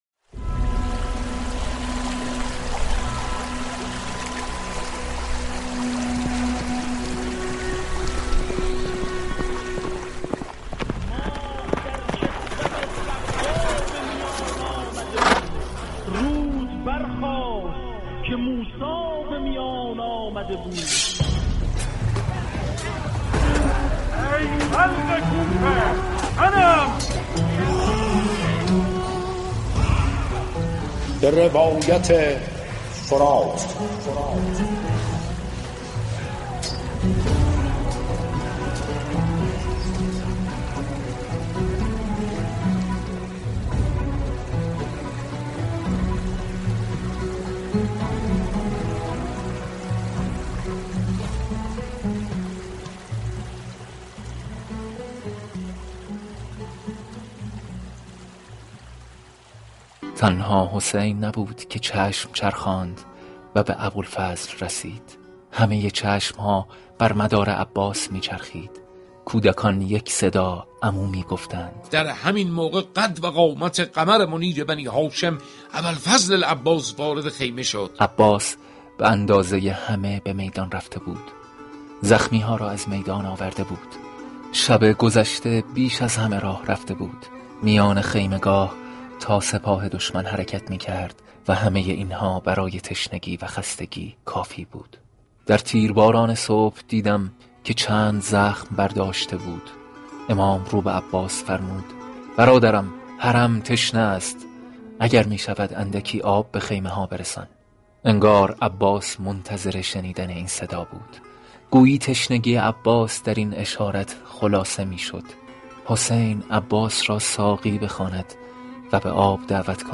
این ویژه برنامه در قالب مستند روایی به روایت واقعه كربلا از زاویه دیده رود فرات می پردازد. بخش عمده این برنامه به رجز و رجز خوانی لشكر امام حسین علیه السلام اختصاص دارد.